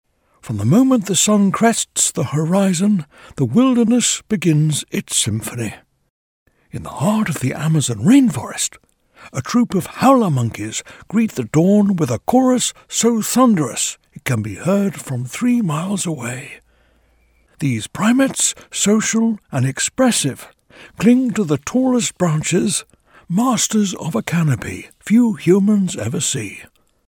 Older Sound (50+)
Warm, articulate British voice with natural authority and clean, confident delivery.
Impersonations
Sir David Attenborough Mimic
0120Attenborough_style.mp3